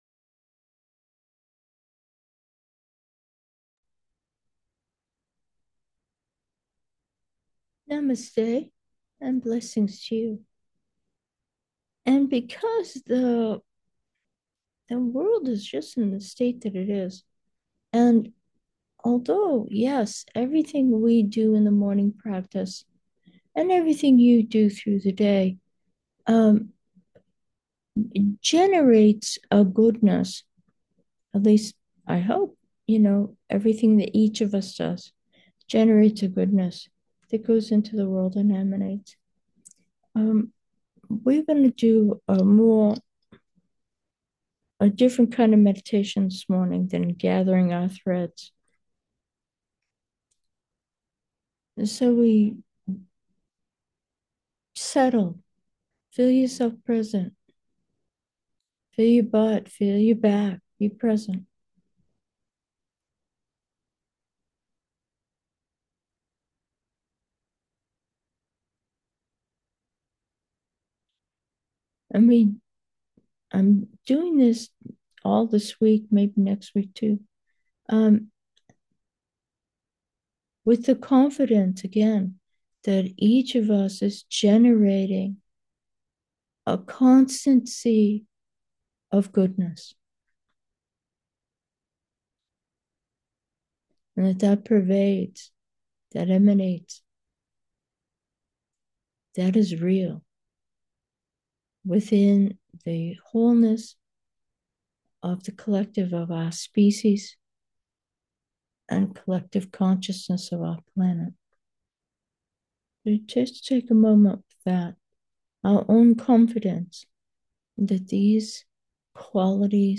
Meditation: we generate a great light 1